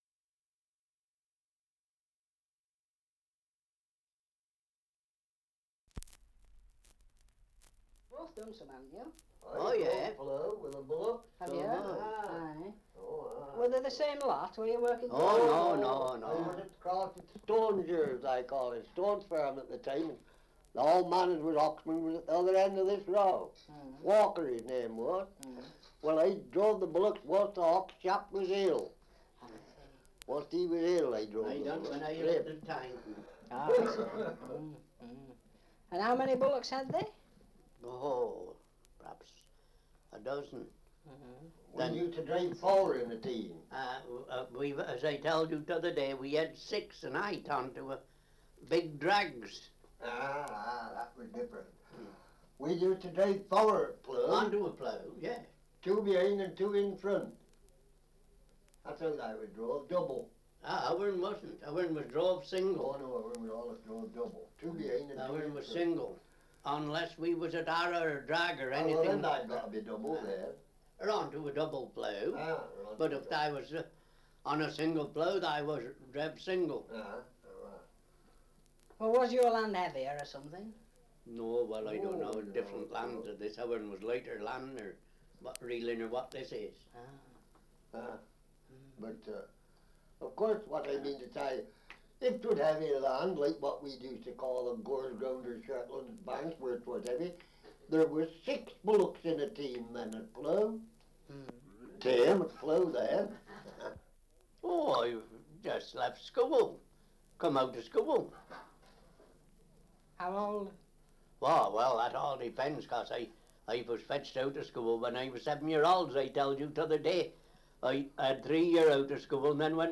Survey of English Dialects recording in Sherborne, Gloucestershire
78 r.p.m., cellulose nitrate on aluminium